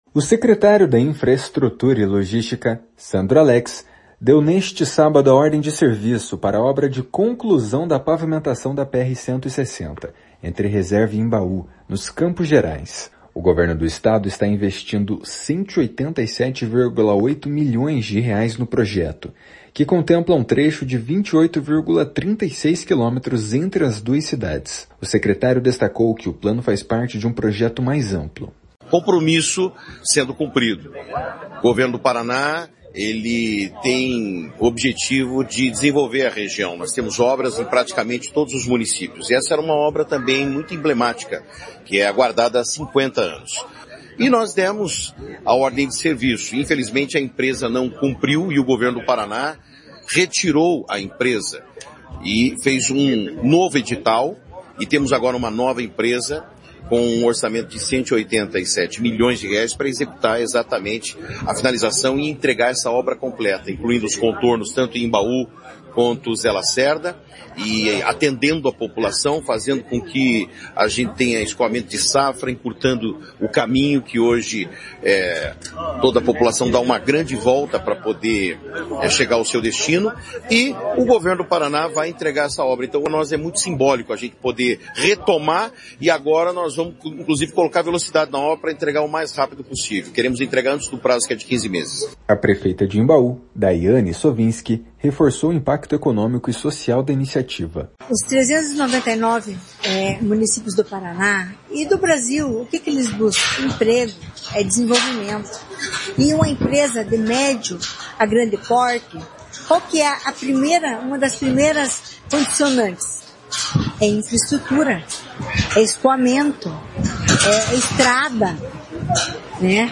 A prefeita de Imbaú, Dayane Sovinski, reforcou o impacto econômico e social da iniciativa.